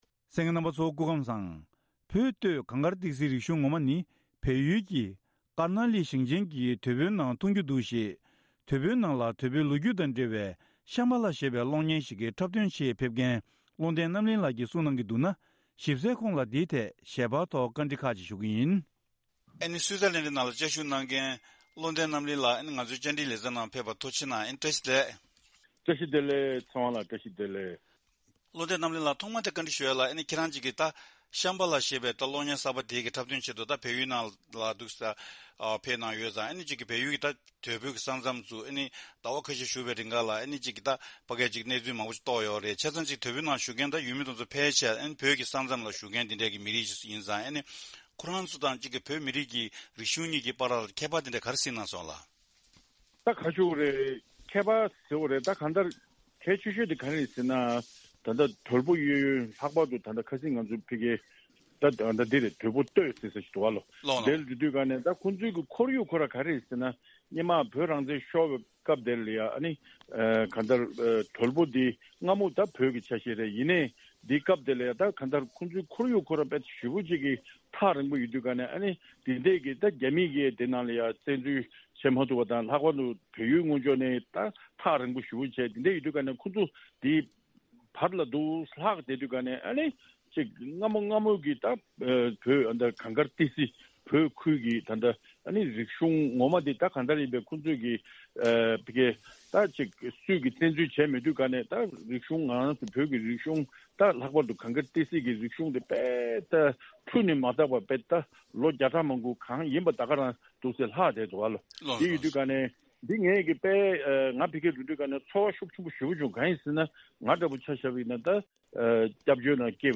བཅའ་འདྲི་ཞུས་པའི་གནས་ཚུལ།